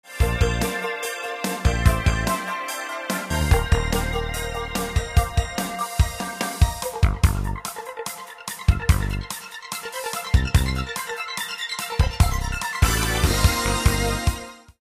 Metoda ta nie generuje dźwięków brzmiących realistycznie, lecz dzięki dodatkowemu stosowaniu zabiegów modulacujnych (LFO, zmiany obwiedni) możliwe jest uzyskanie ciekawych brzmień syntetycznych.
Przykładowe syntezatory wykorzystujące syntezę wavetable.